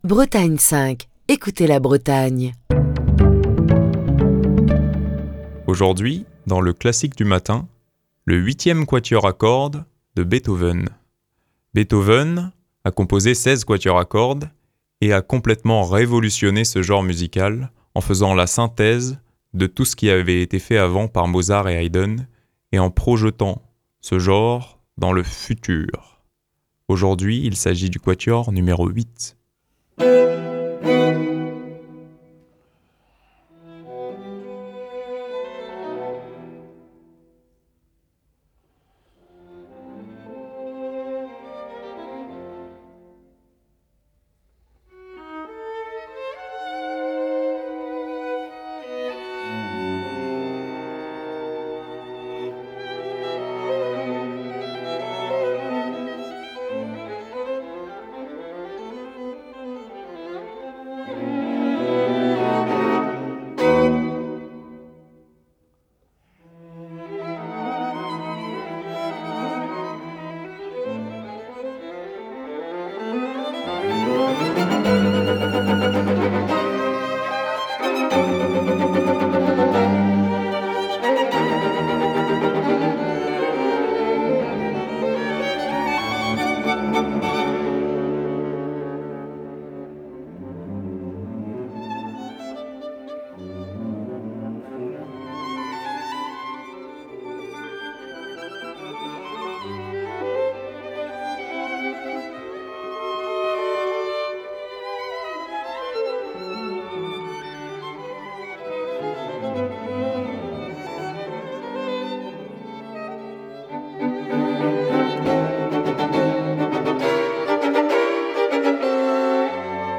Ludwig van Beethoven - Le Quatuor à cordes n° 8 | Bretagne5